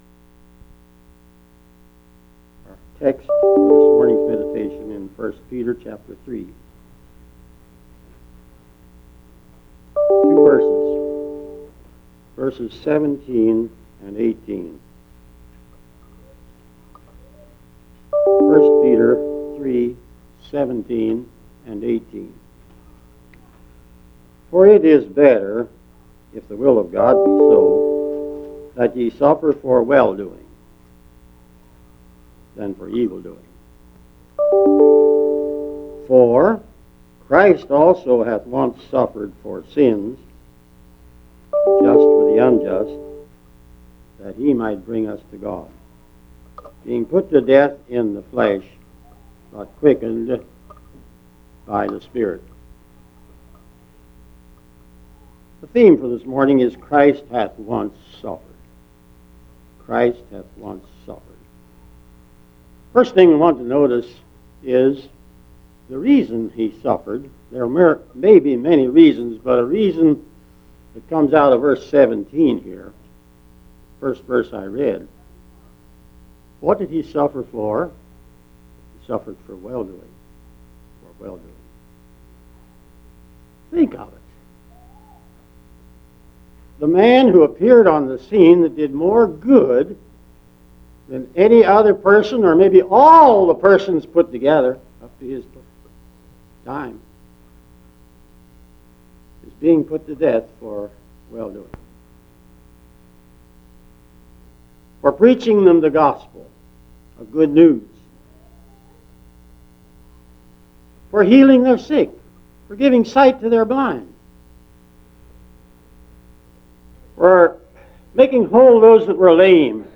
This is a communion message, and it highlights the doctrinal aspects of Jesus' sufferings for us.